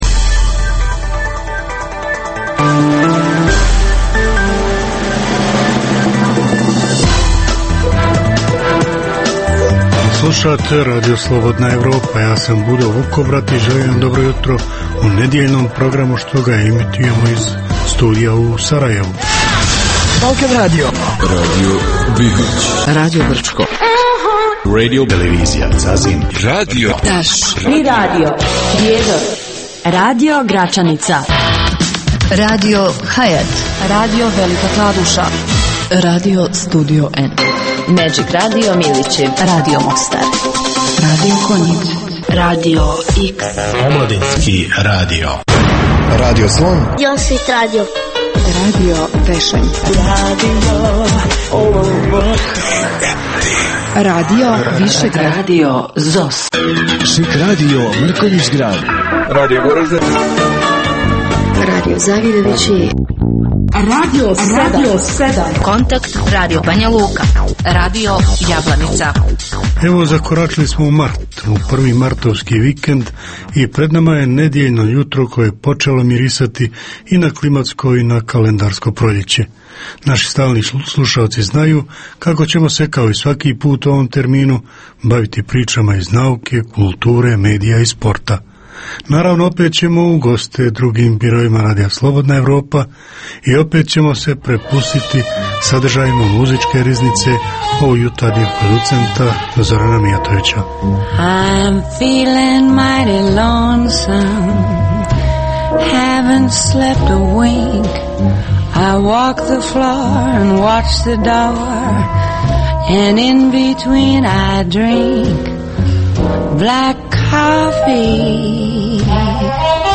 Jutarnji program namijenjen slušaocima u Bosni i Hercegovini. Uz vijesti i muziku, poslušajte pregled novosti iz nauke i tehnike, te čujte šta su nam pripremili novinari RSE iz regiona.